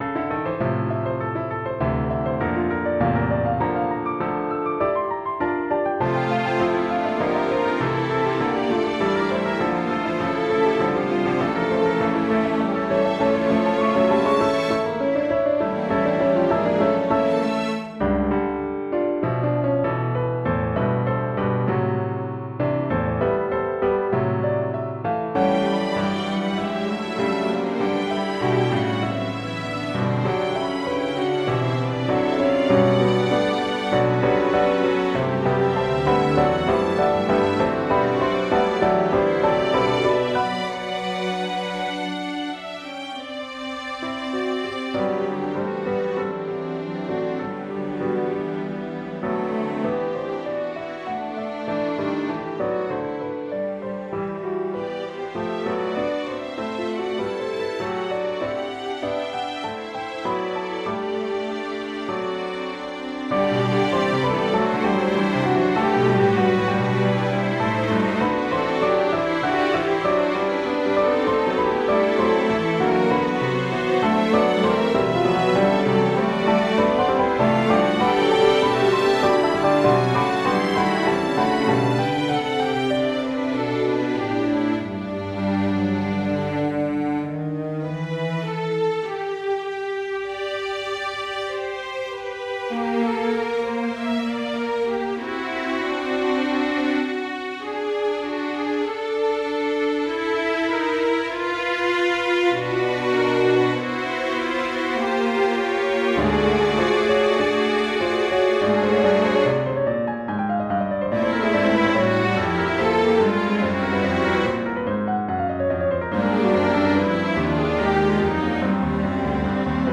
Hi Everyone, I have started writing this piano work including a Piano solo and the string orchestra.